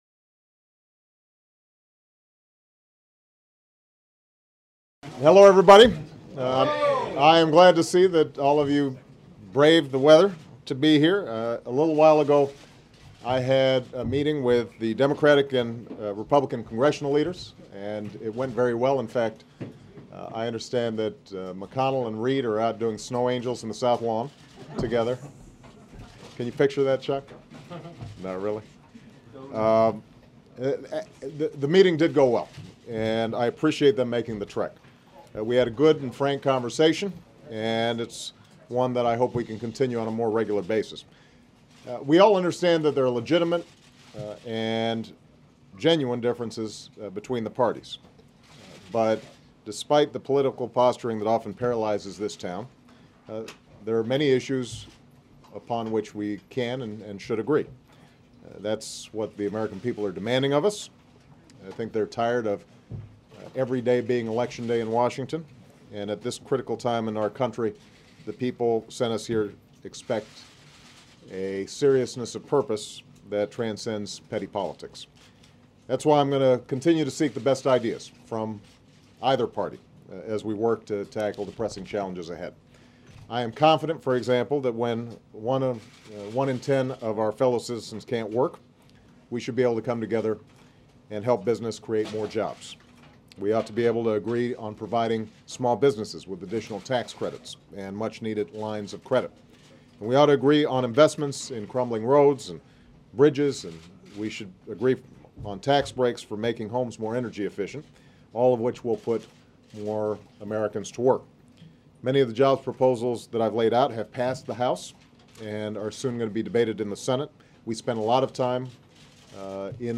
February 9, 2010: News Conference on Congressional Gridlock